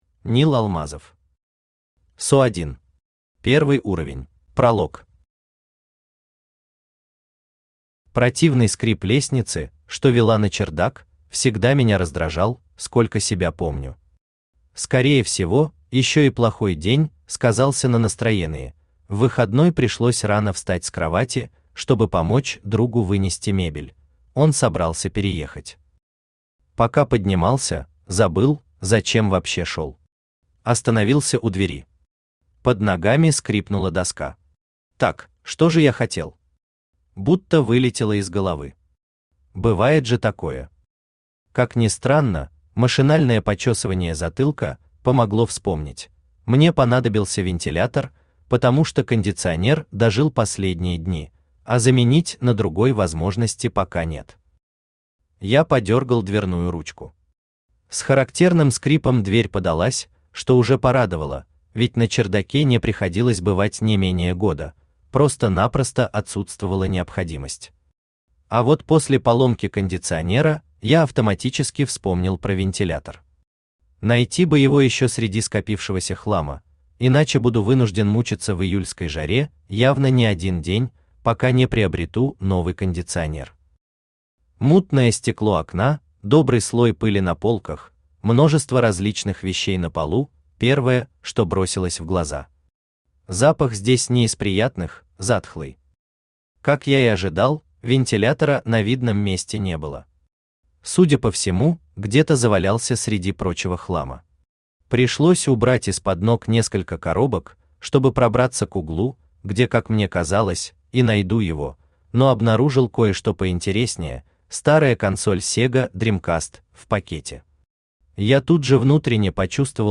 Аудиокнига PSO 1. Первый уровень | Библиотека аудиокниг
Первый уровень Автор Нил Алмазов Читает аудиокнигу Авточтец ЛитРес.